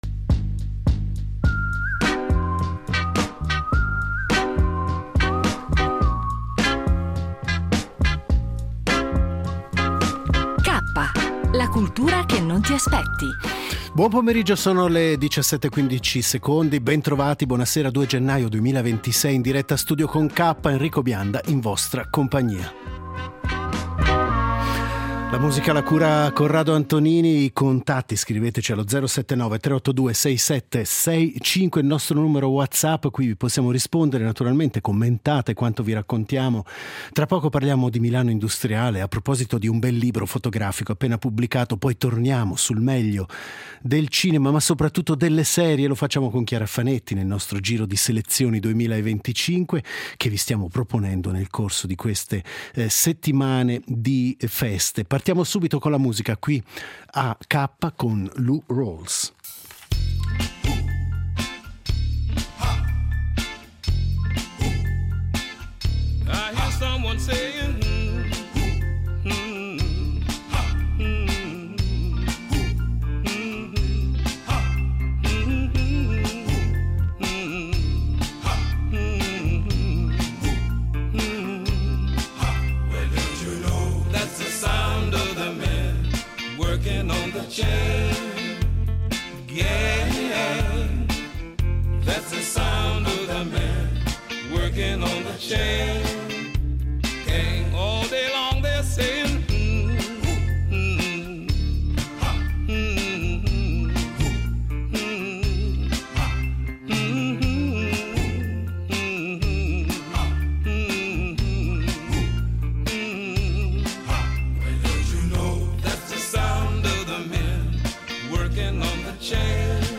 Il tutto è arricchito da un gioco a premi per gli ascoltatori e una variegata selezione musicale.